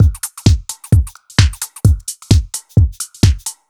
Index of /musicradar/uk-garage-samples/130bpm Lines n Loops/Beats
GA_BeatnPercE130-02.wav